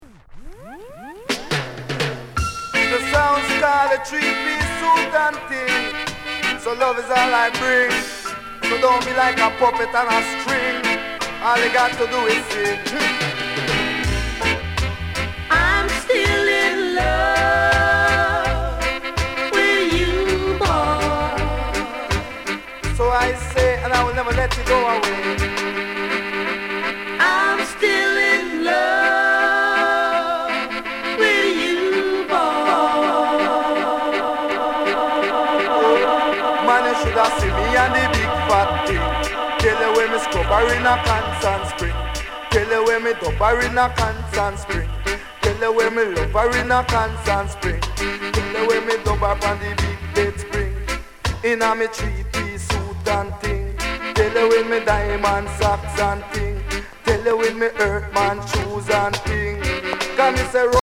DEE JAY CUT